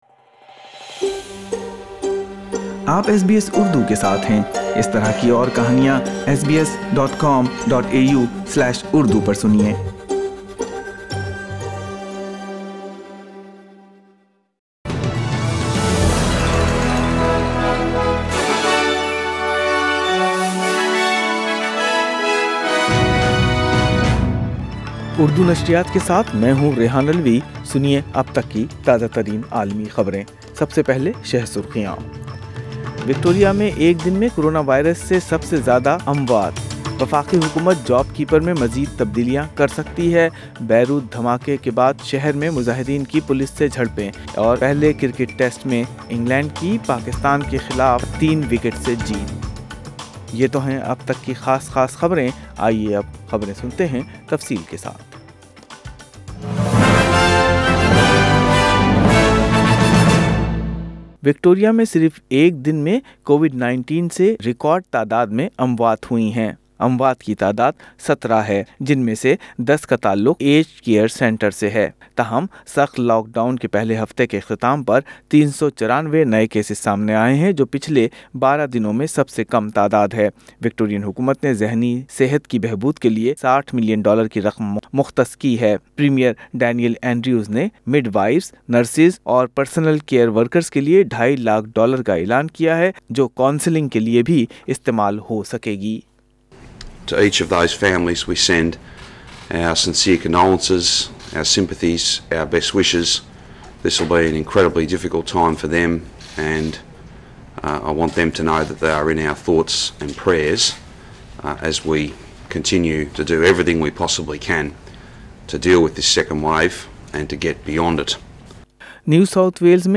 اردو خبریں 9 اگست 2020